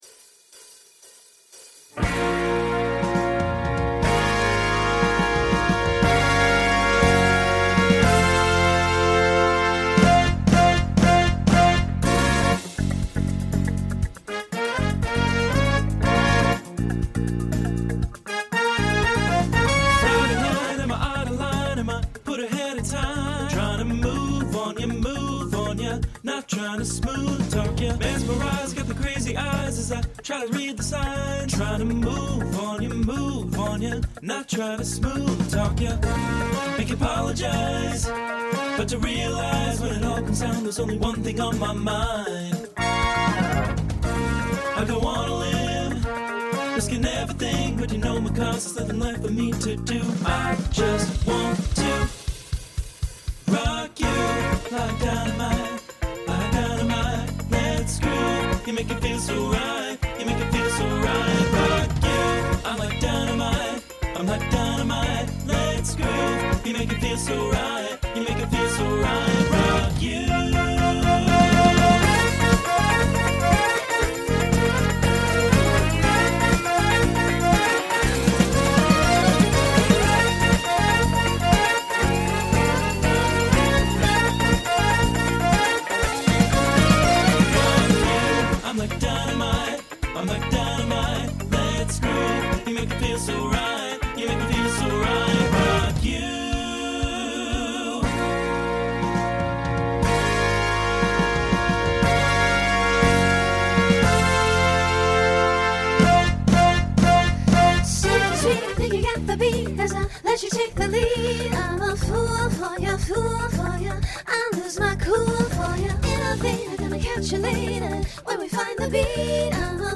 Starts TTB, then SSA, then SATB.
Genre Pop/Dance , Rock
Transition Voicing Mixed